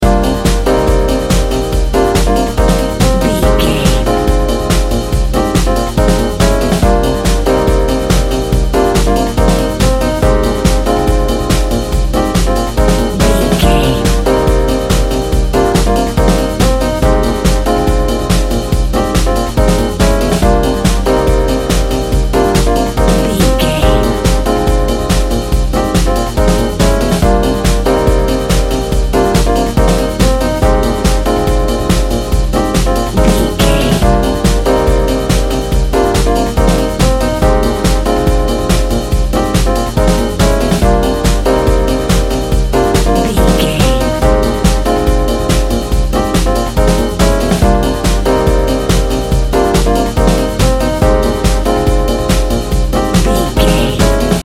Ionian/Major
E♭
Fast
groovy
futuristic
driving
energetic
cheerful/happy
repetitive
electric piano
synthesiser
drum machine
instrumental music
synth leads
synth bass